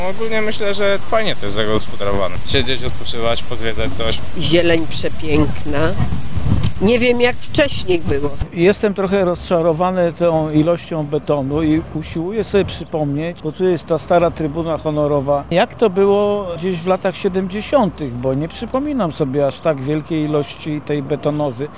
Co myślą o nowym placu warszawiacy?